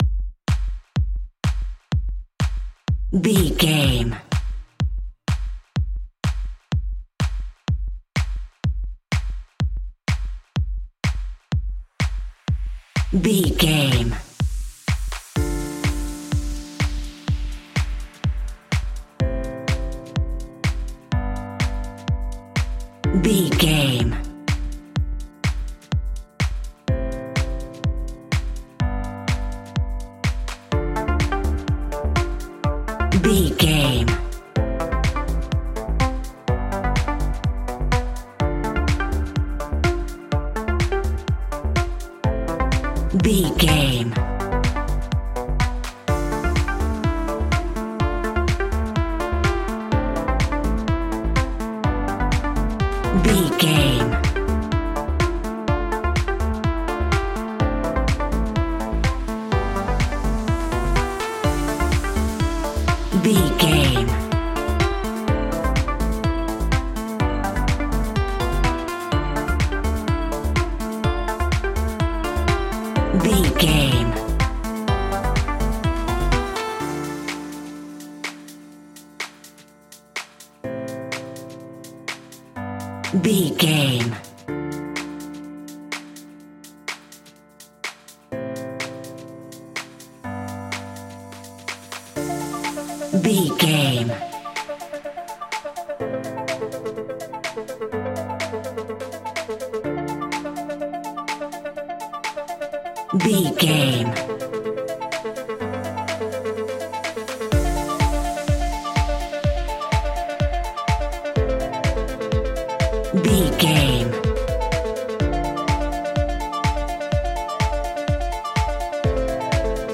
Aeolian/Minor
energetic
hypnotic
funky
drum machine
synthesiser
electro house
progressive house
synth bass